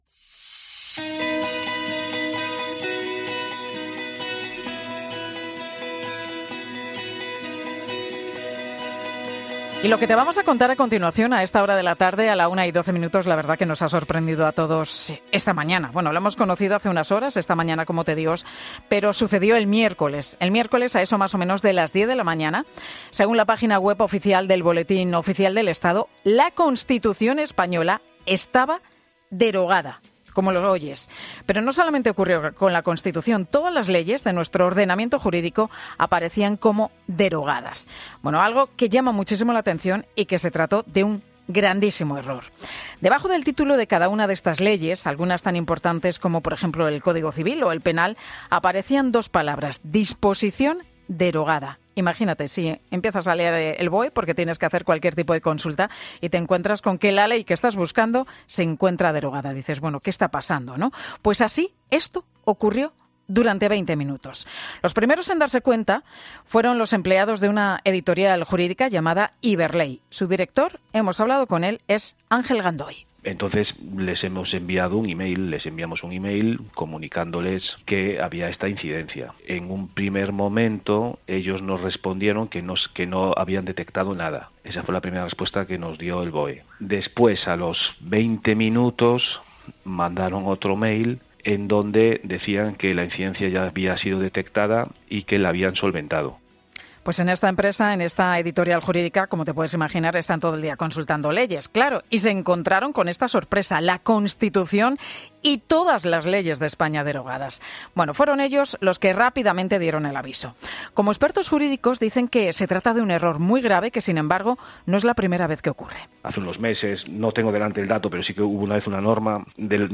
Un catedrático de Derecho Constitucional explica en COPE qué pudo ocurrir con la derogación de la Constitución